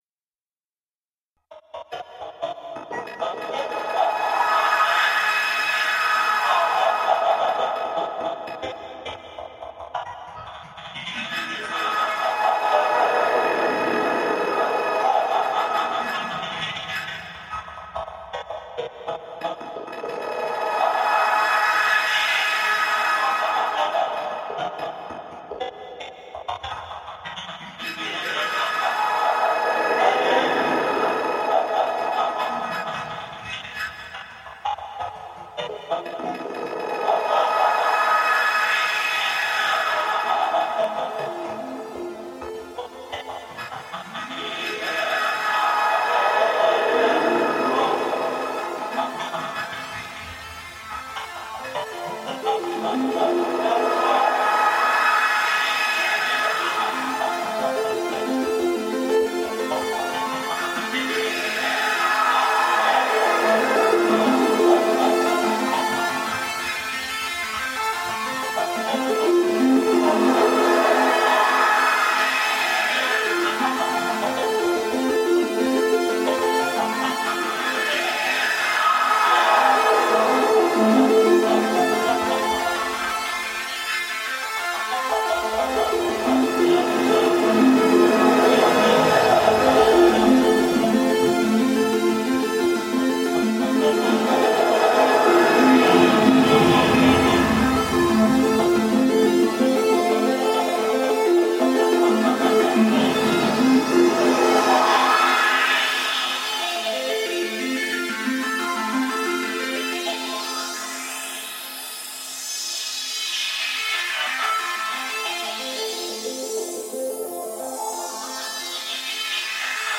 Granulising some guitar and voice music played through a tinny carpark speaker.